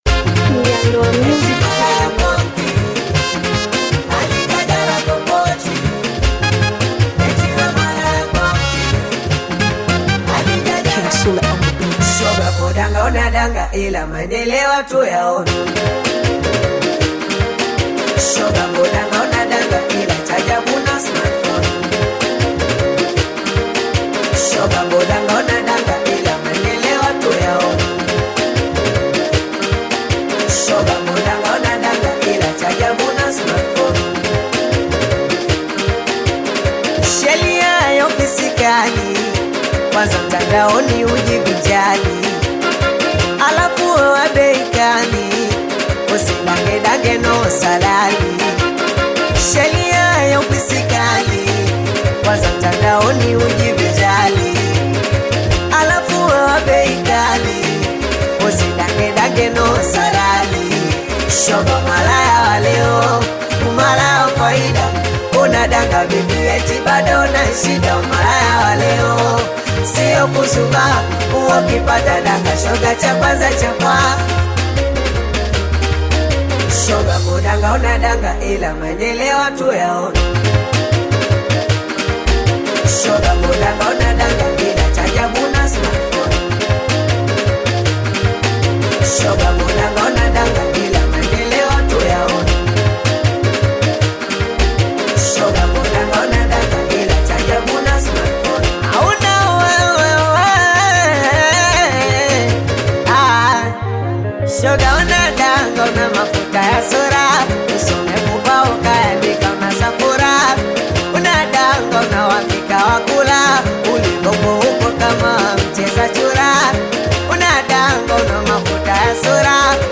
Tanzanian Bongo Flava singeli
vibrant singeli song